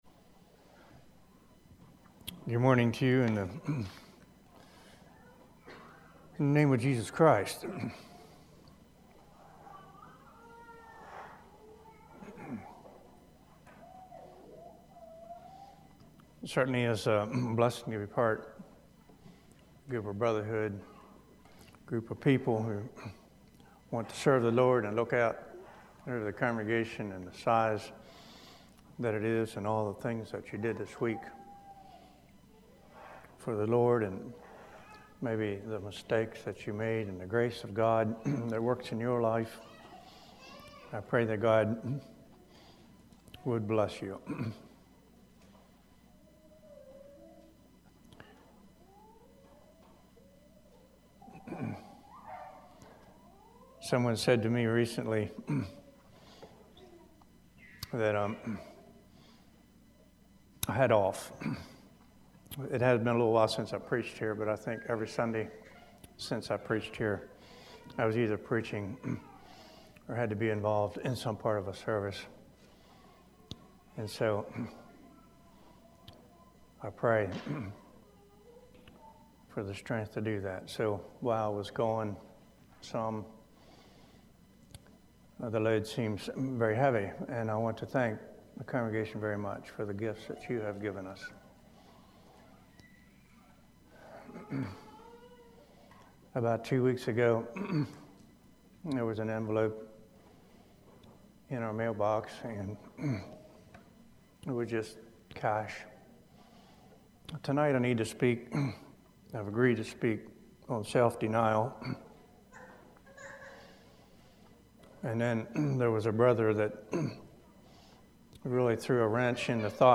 Play Now Download to Device The Doctrine of Unleavened Bread Congregation: Calvary Speaker